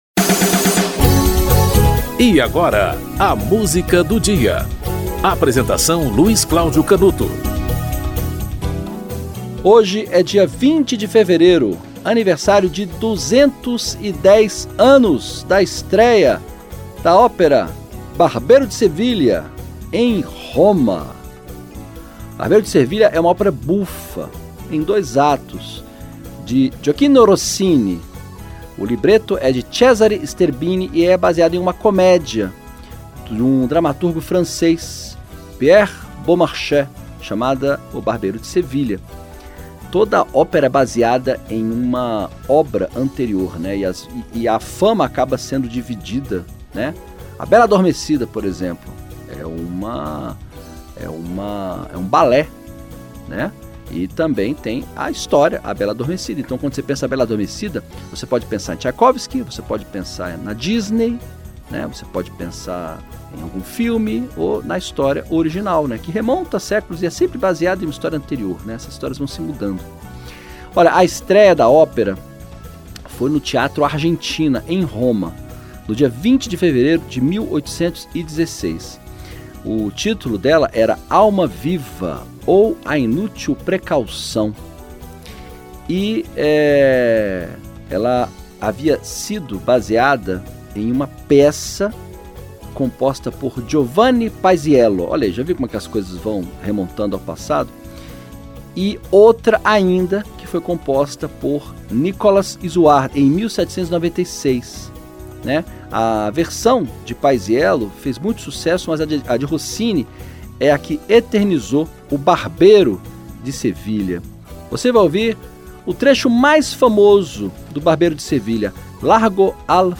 Hoje é 20 de fevereiro, aniversário de 210 anos da estréia em Roma da ópera-bufa O Barbeiro de Sevilha em Roma
Peter Mattei - Largo al Factotum (Gioachino Rossini)